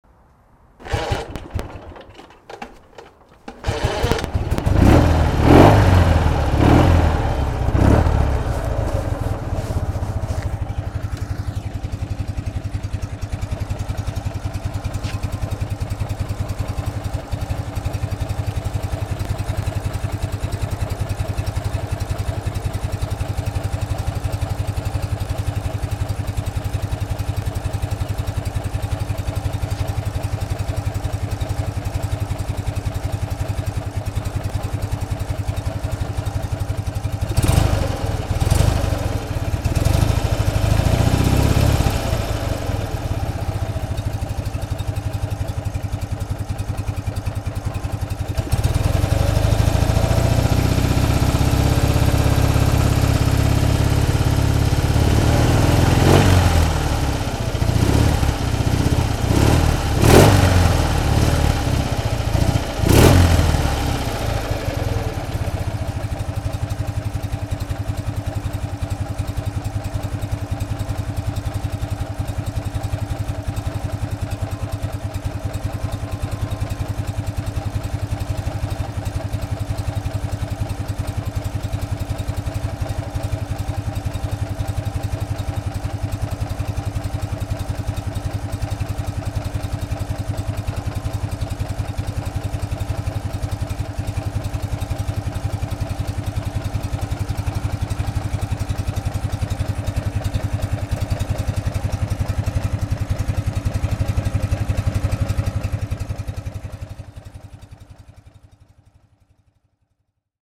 Here is a sound clip that more accurately reflects the way the motorcycle sounds:
R60-motorljud.mp3